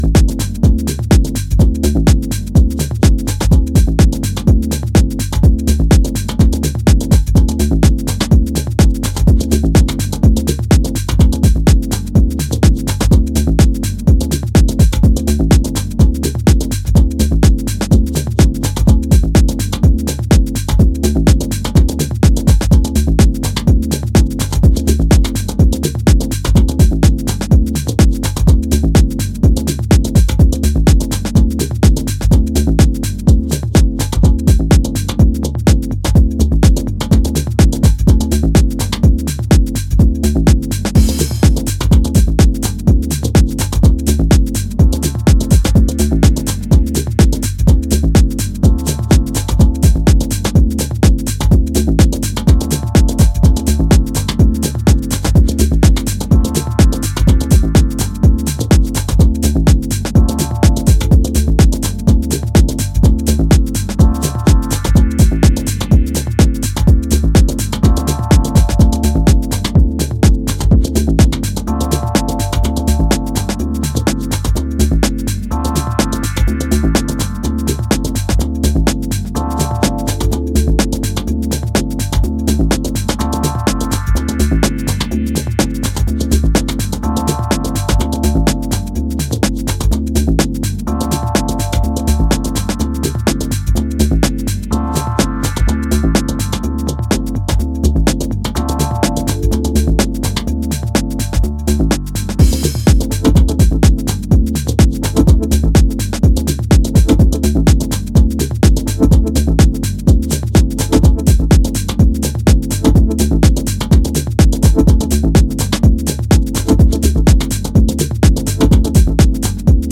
three cuts crafted for the dancefloor